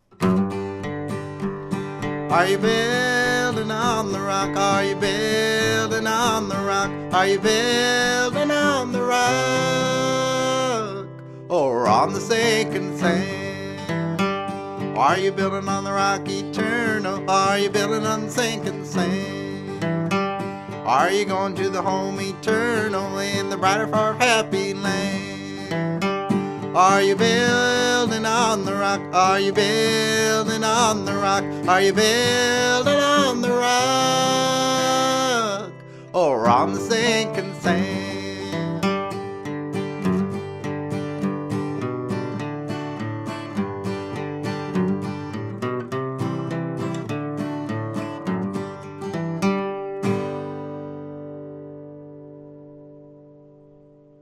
key of G